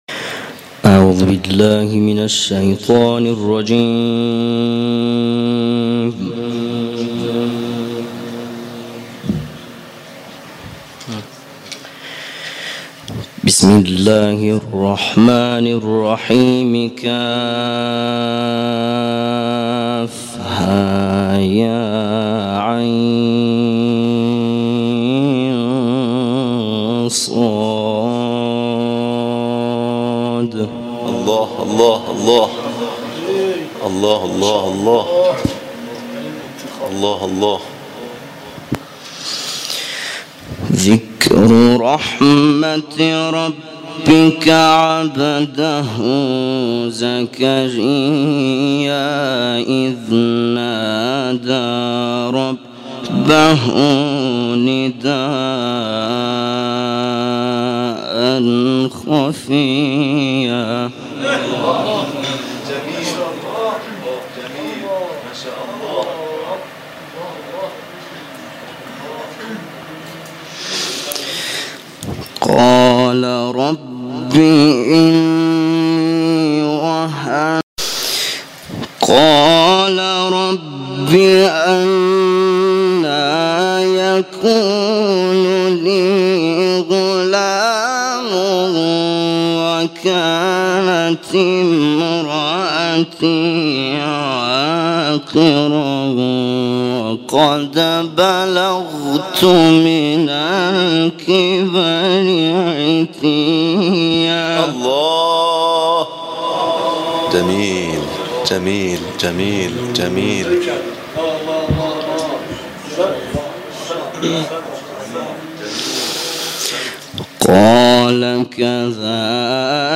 تلاوت آیات 1 تا 15  از سوره مبارکه مریم